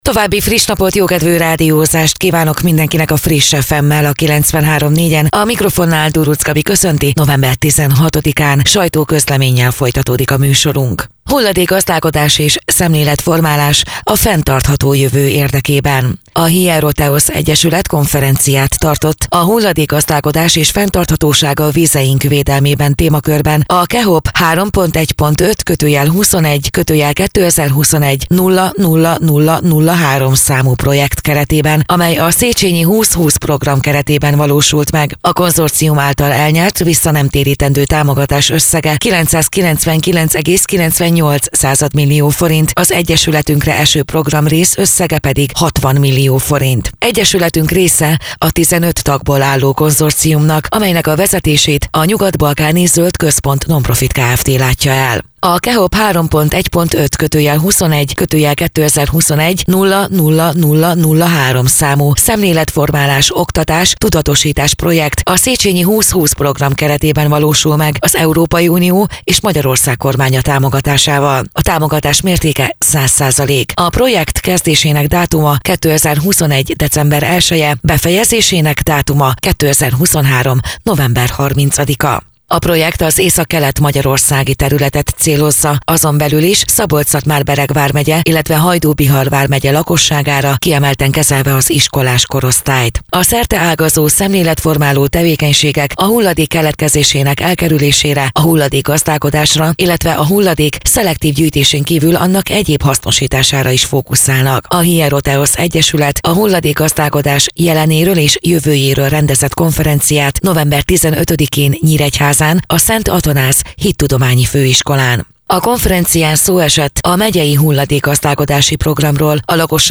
Rádió
2023. november 15.: Nyíregyháza - Hulladékgazdálkodás és szemléletformálás a fenntartható jövő érdekében” című konferenciára - sajtóközlemény